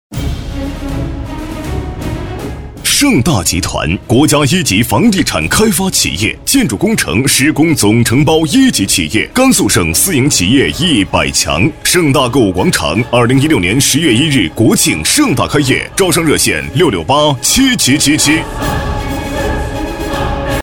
Nice voices
【广告】晟大集团简介男75-磁性大气
【广告】晟大集团简介男75-磁性大气.mp3